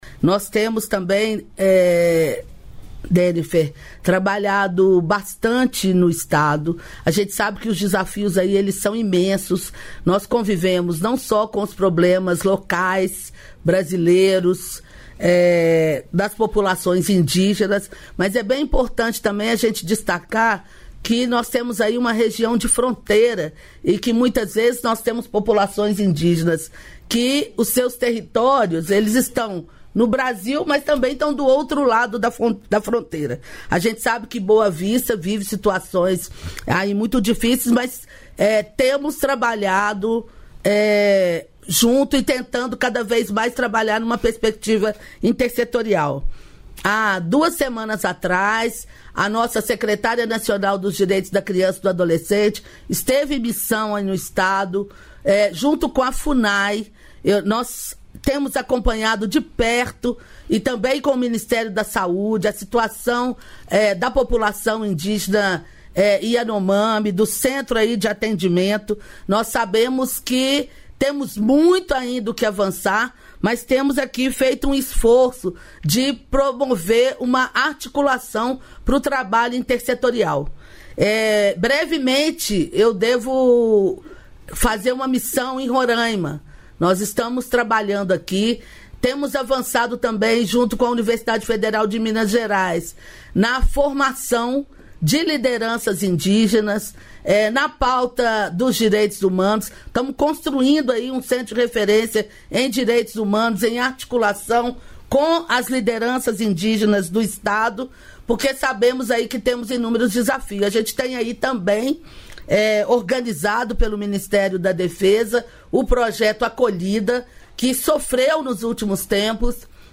Trecho da participação da ministra dos Direitos Humanos e da Cidadania, Macaé Evaristo, no programa "Bom Dia, Ministra" desta quinta-feira (15), nos estúdios da EBC em Brasília (DF).